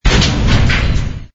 tractor_loot_grabbed.wav